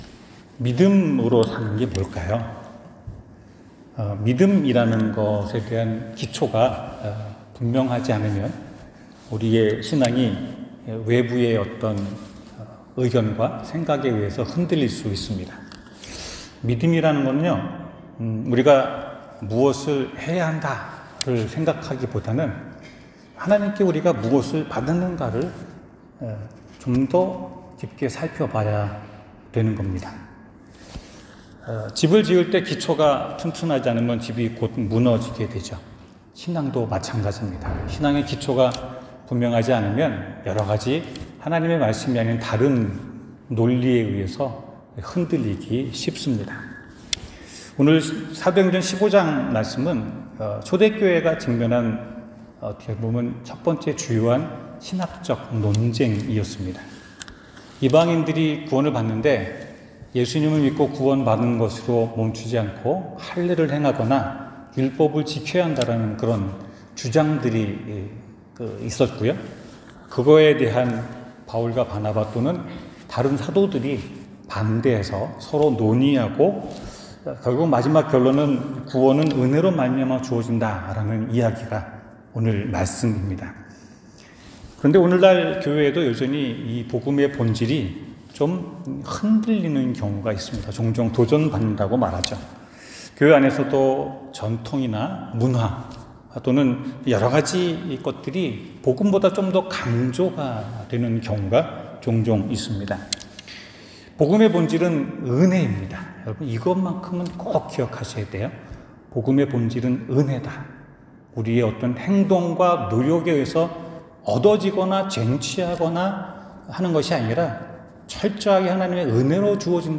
성경 : 사도행전 15:1-21 설교